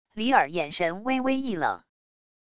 李尔眼神微微一冷wav音频生成系统WAV Audio Player